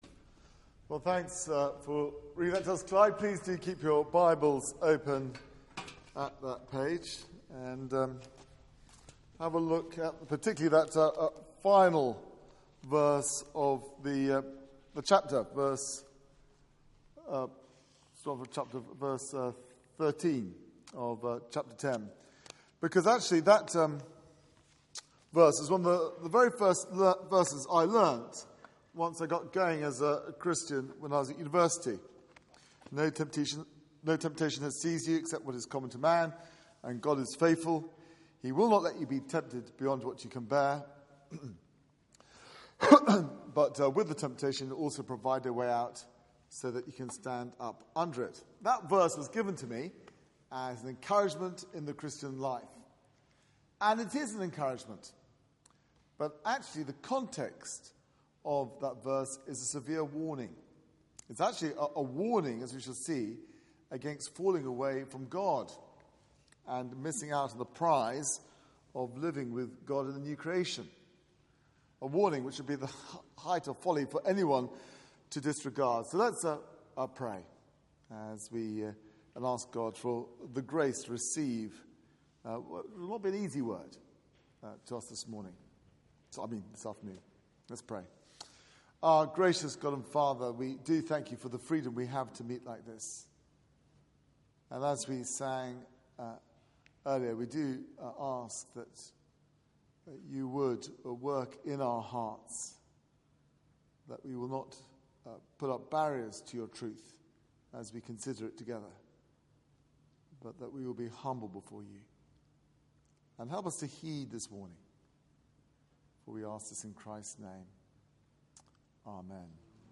Media for 4pm Service on Sun 07th Jun 2015 16:00 Speaker
Theme: Sermon Search the media library There are recordings here going back several years.